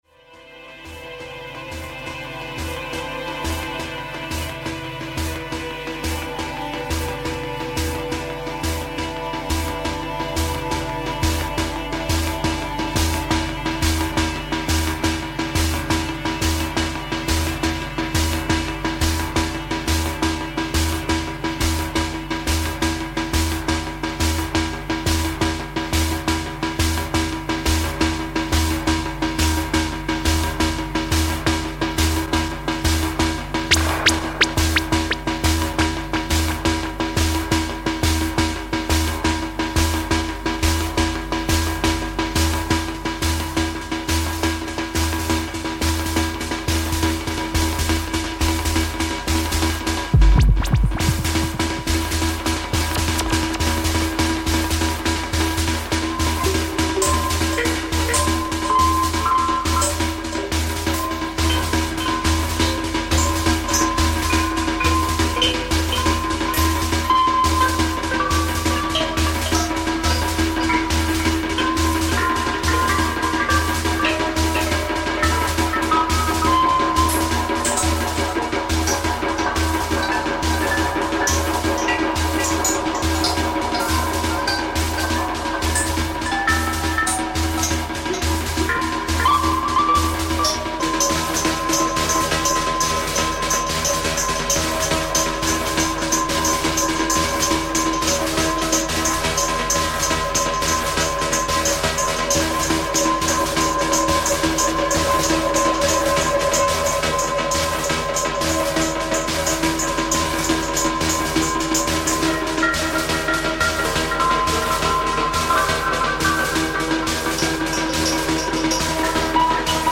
Early Electronics